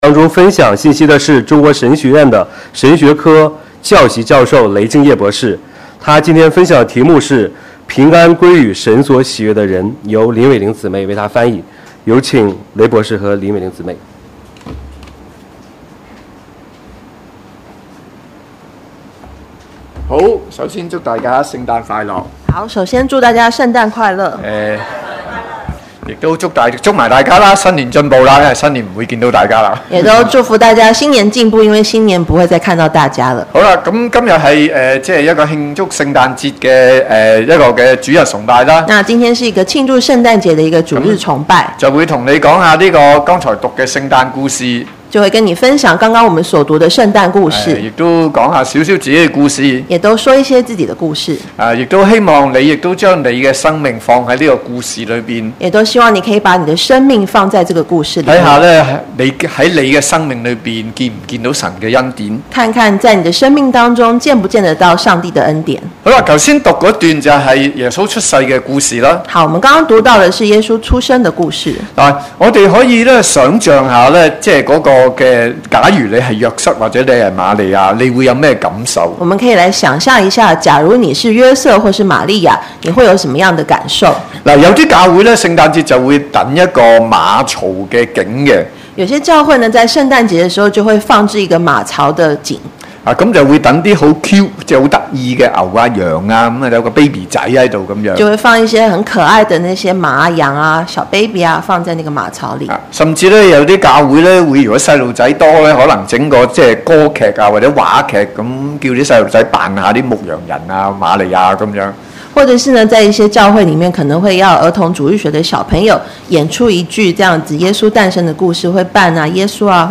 Posted in 主日崇拜